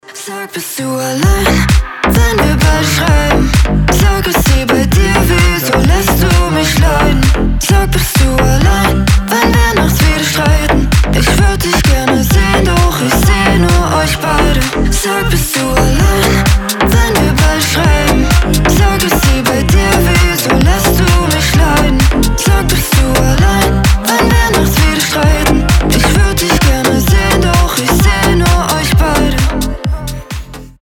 • Качество: 320, Stereo
slap house